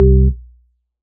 55bm-org01-c3.aif